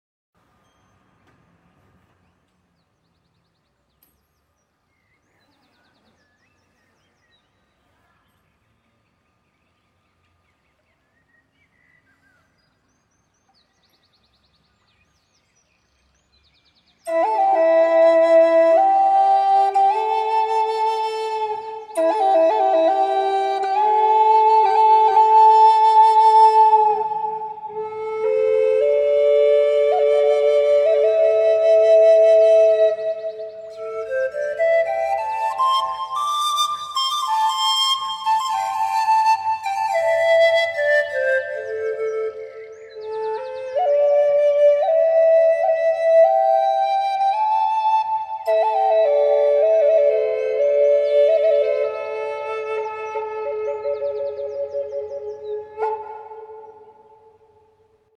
• Material: Madeira de alta qualidade
• Afinação: Am (Lá menor)
• Sonoridade Rica: A flauta produz tons profundos e expressivos, capturando a essência das músicas tradicionais nativas.
El-encuentro-del-Aguila-con-el-Condor-Flauta-tripla-Am.mp3